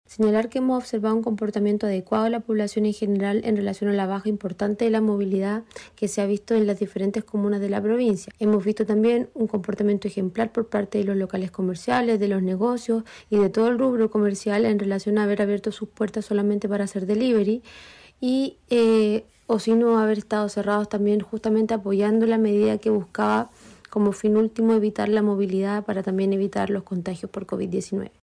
Así lo informó la jefa de la Autoridad Sanitaria, María Fernanda Matamala, quien valoró la excelente conducta de las personas, que acataron las disposiciones dispuestas por la autoridad, como también lo hizo el comercio.